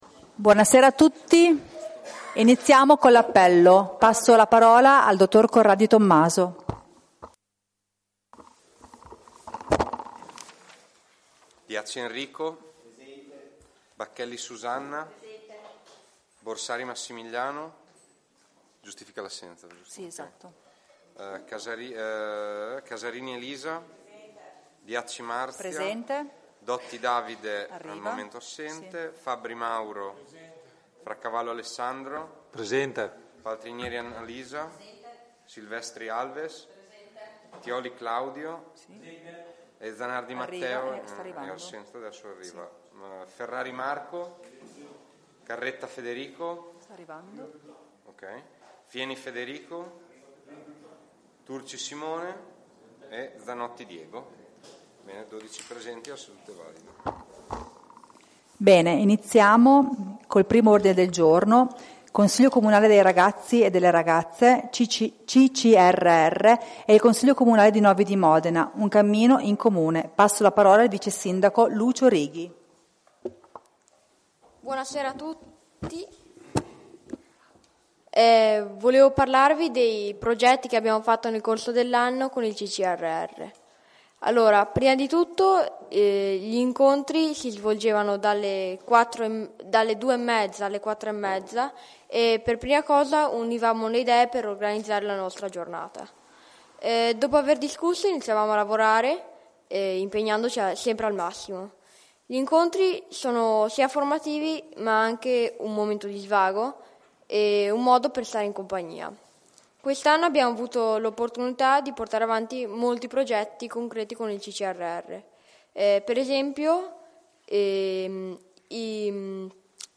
Seduta del 30/05/2024